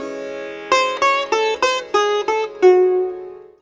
SITAR LINE26.wav